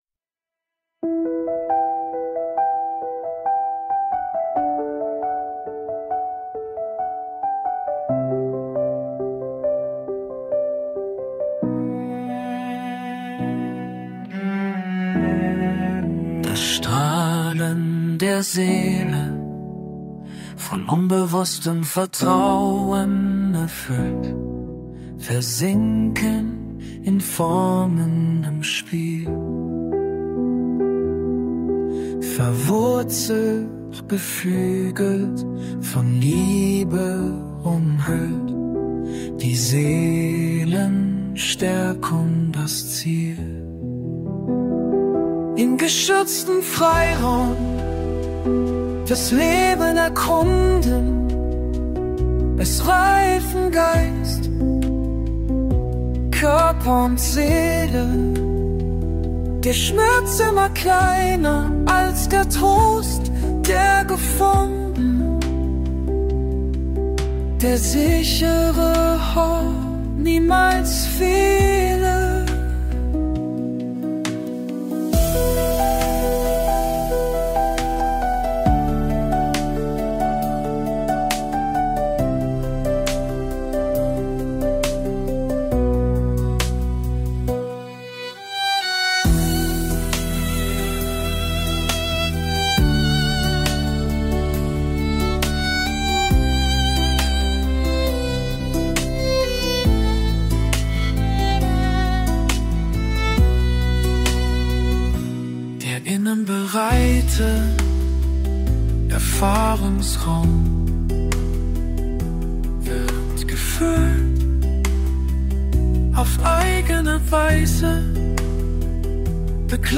(KI-gestütztes Audiodesign)
Gelesen von mir